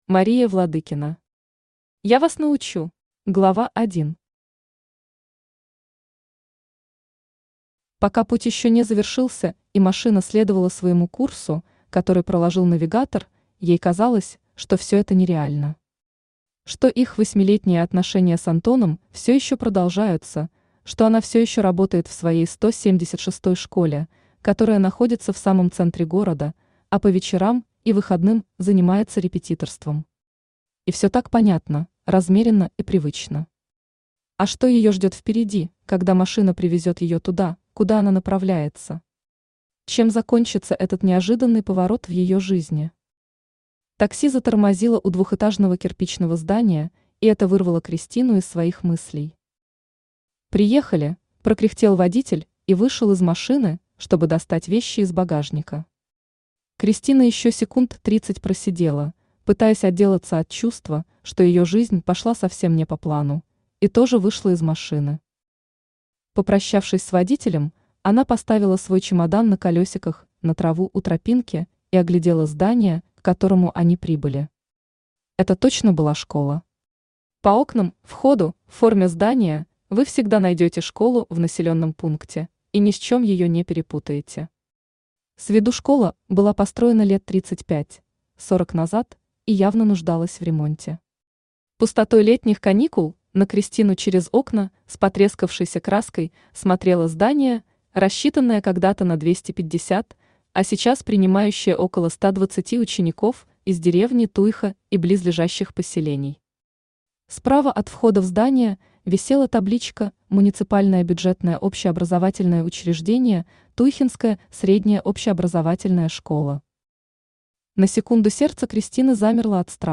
Аудиокнига Я Вас научу | Библиотека аудиокниг
Читает аудиокнигу Авточтец ЛитРес.